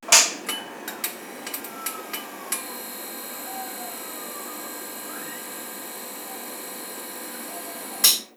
Encender y apagar unos fluorescentes
Sonidos: Oficina